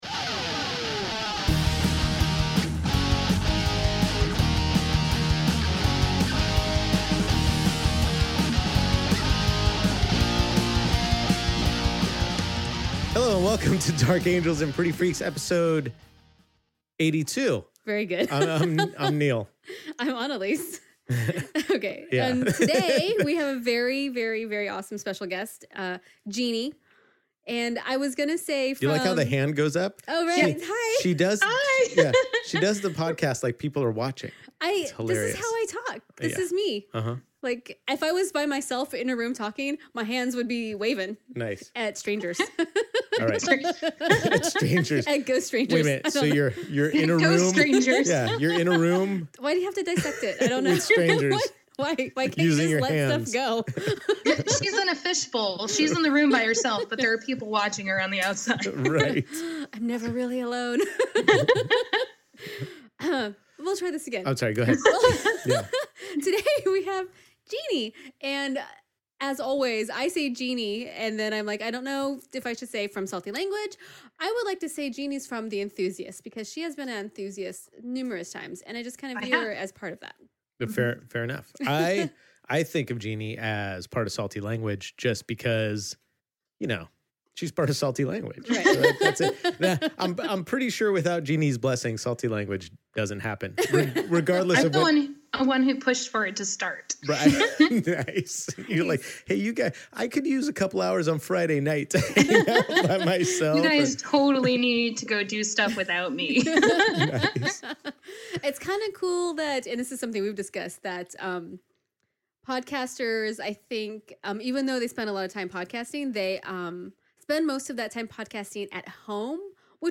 We talk love, life, family, and sex! A great conversation with great people!